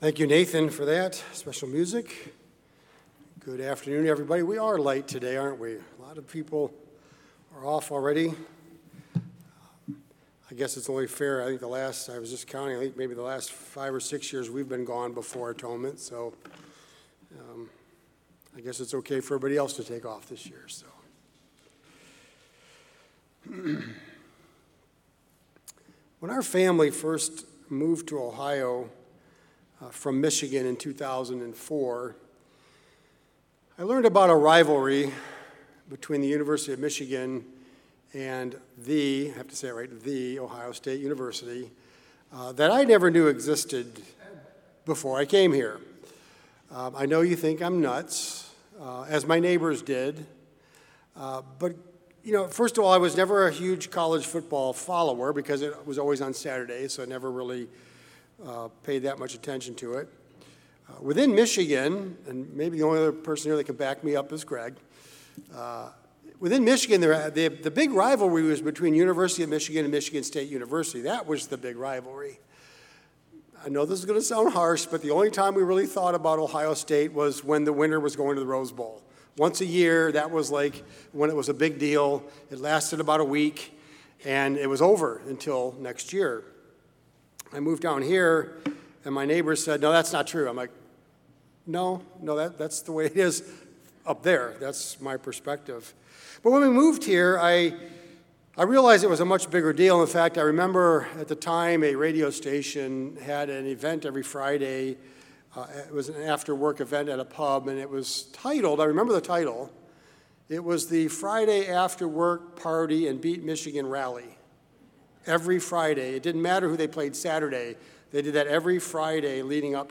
Sermons
Given in Columbus, OH